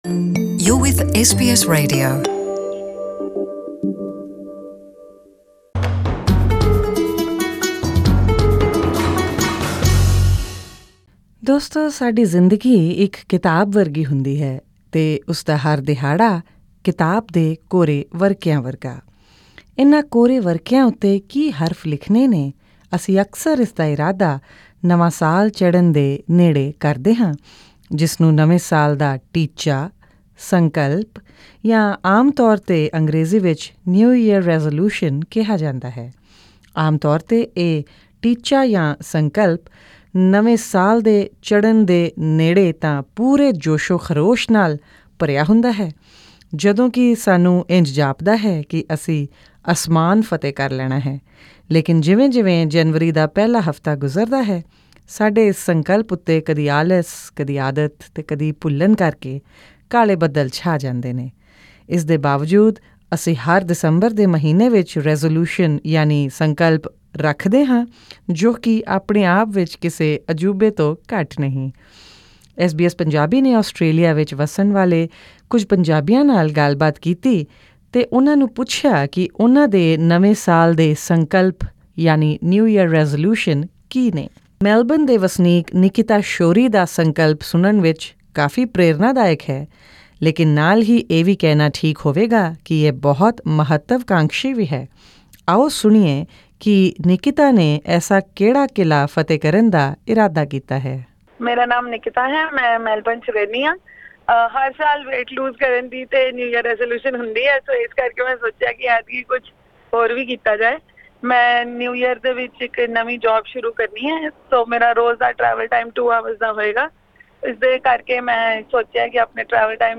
In conversation with SBS Punjabi, Aussie Punjabis lay out their desires for 2019.